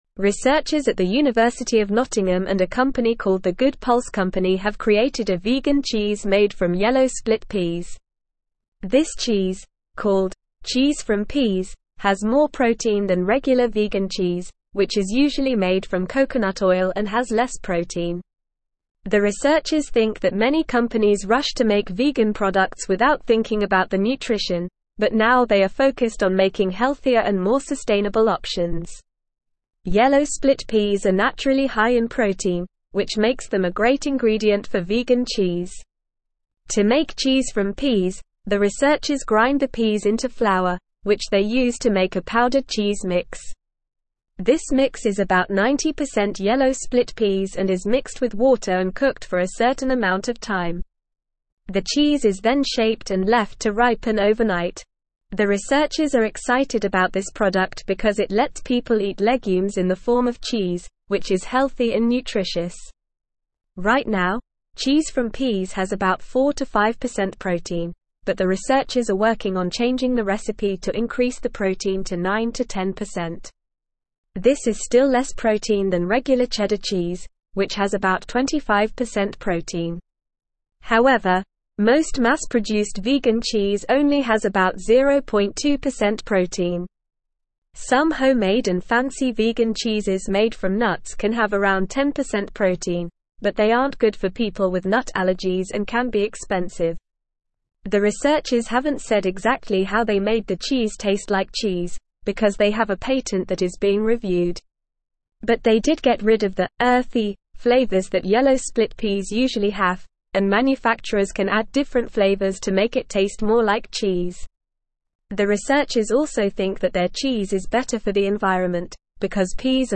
Normal
English-Newsroom-Upper-Intermediate-NORMAL-Reading-Vegan-Cheese-Made-from-Peas-High-Protein-Sustainable.mp3